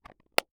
Case Plastic Small Close Sound
household
Case Plastic Small Close